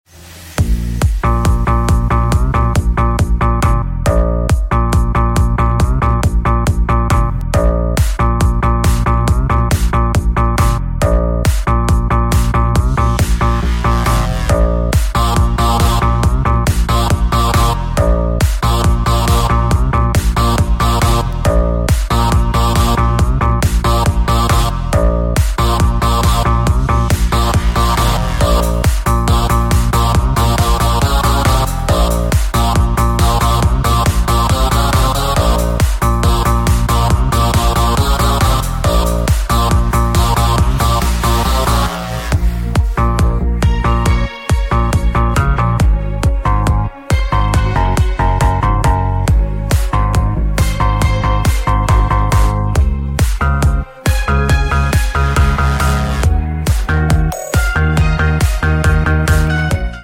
Hehe sound effects free download